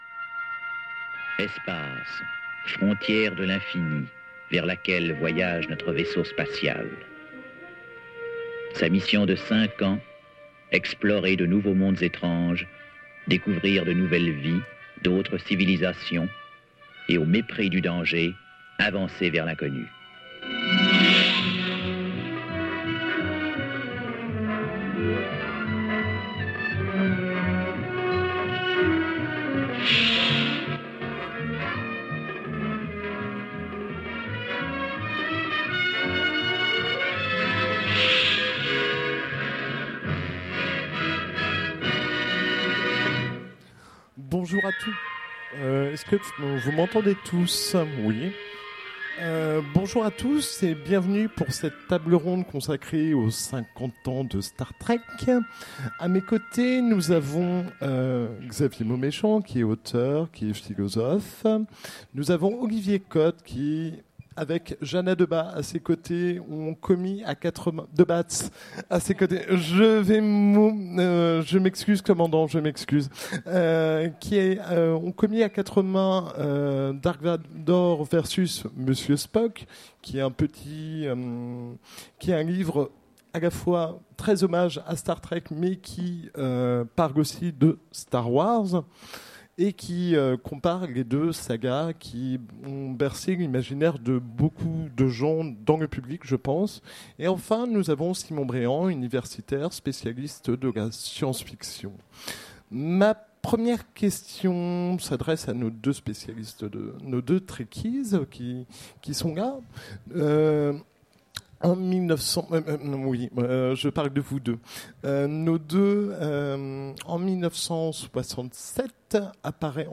Utopiales 2016 : Conférence Les 50 ans de Star Trek